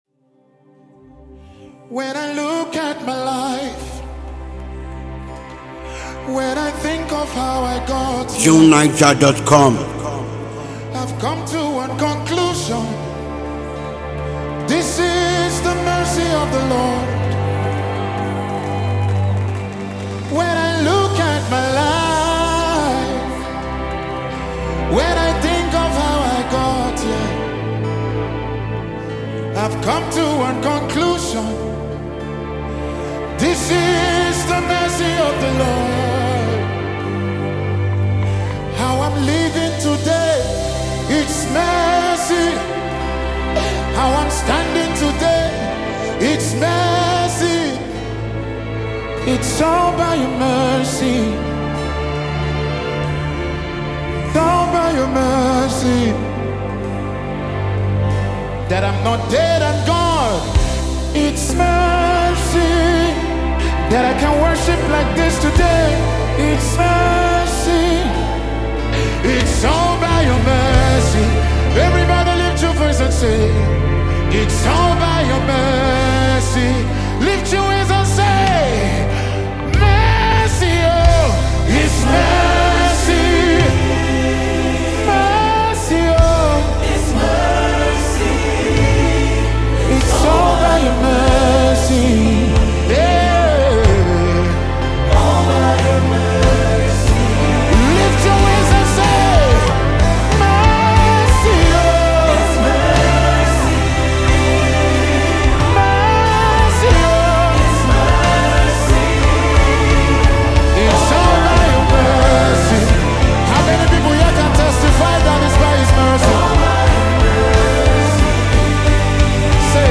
an extraordinary Nigerian gospel singer